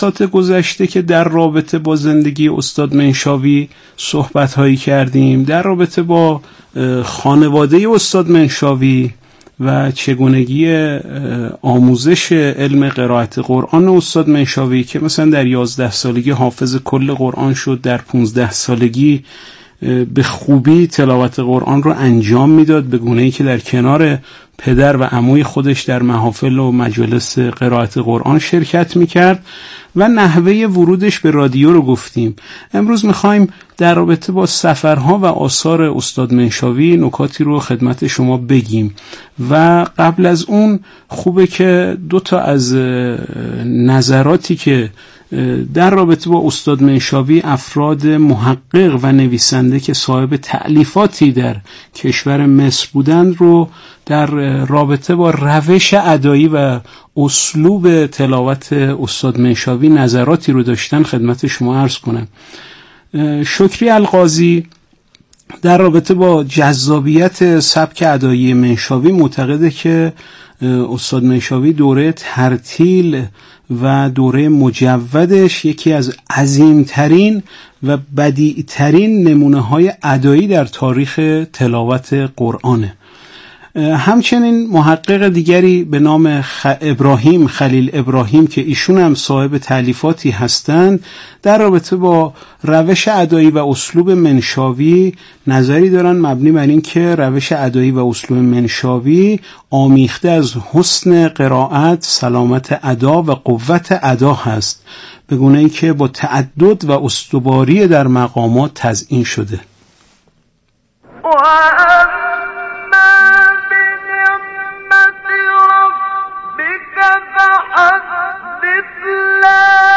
گروه فعالیت‌های قرآنی ــ قاری شهیر مصری طی عمر کوتاه خود سفرهای تأثیرگذار بسیار زیادی به کشورهای مختلف داشت که تلاوت‌های بسیار فاخری را نیز در این سفرها از خود به یادگار گذاشته است.